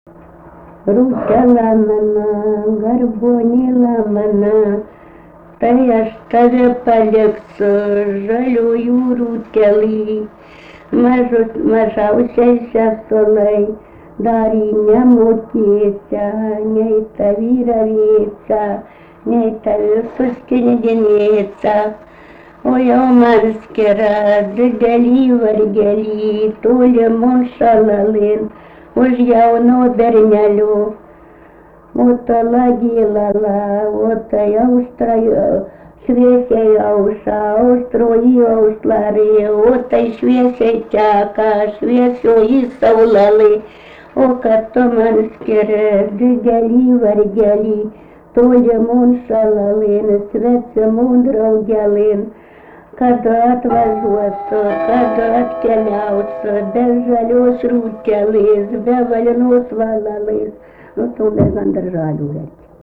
daina, vaikų
Erdvinė aprėptis Rudnia
Atlikimo pubūdis vokalinis